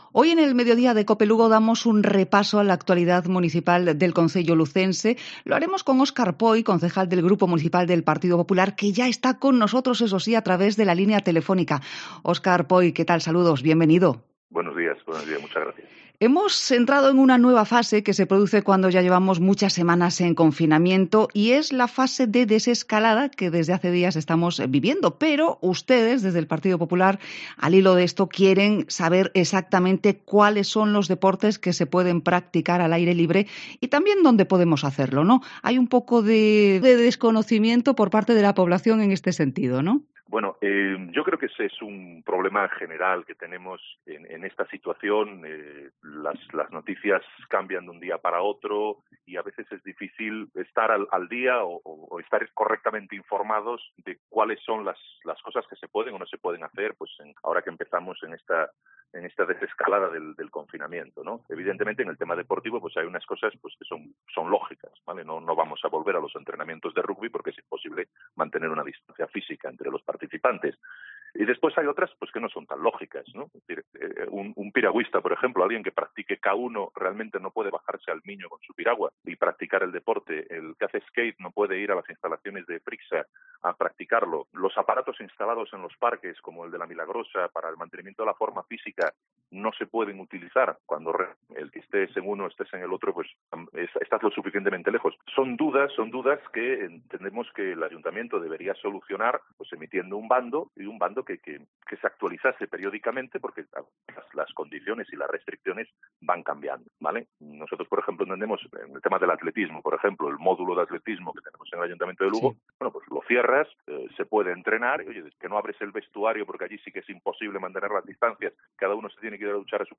Entrevista con Óscar Poy, concejal del Partido Popular de Lugo